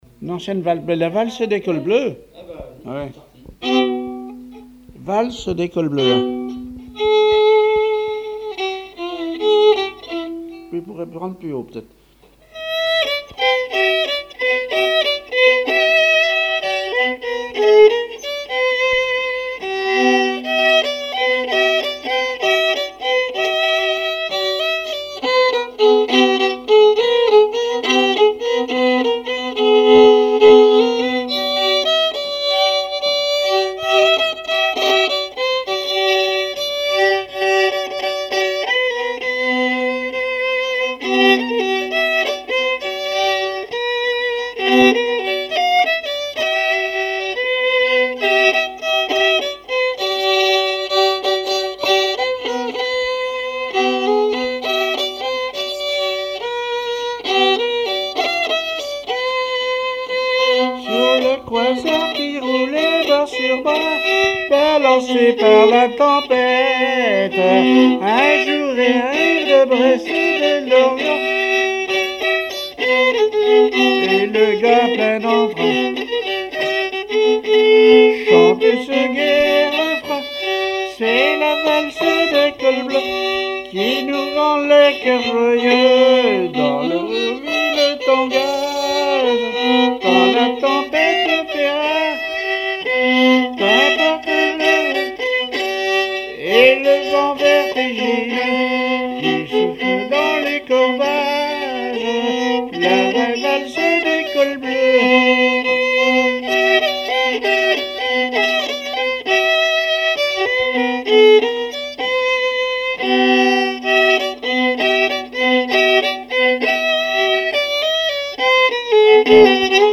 danse : valse
répertoire musical au violon
Pièce musicale inédite